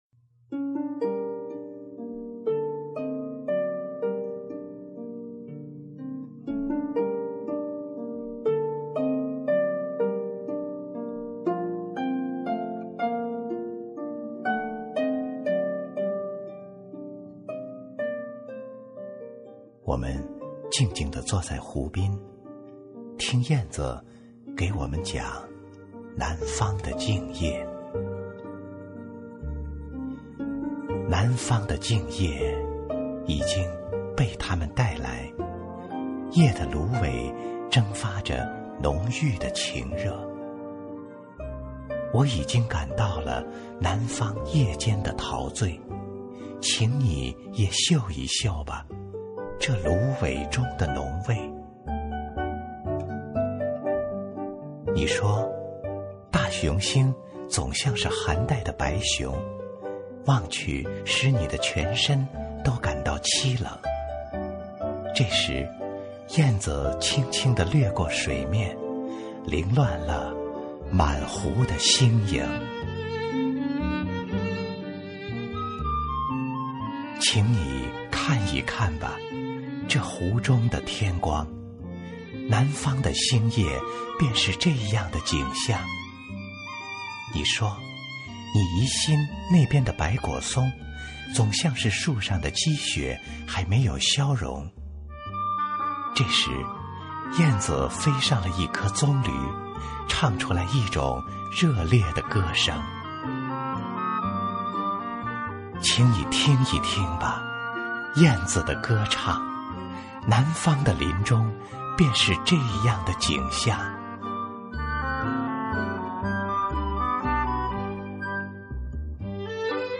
经典朗诵欣赏
新派朗诵——用声音阐释情感